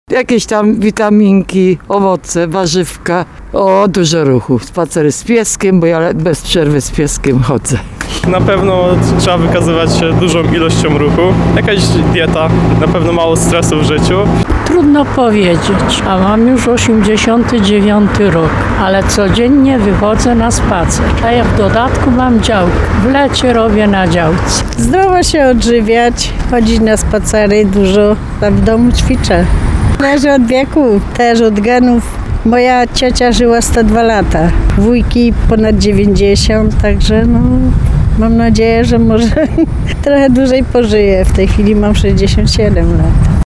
Mieszkańcy Tarnowa przekonują, że sekret długowieczności tkwi w prostych zasadach: ruch, zdrowe jedzenie i jak najmniej stresu.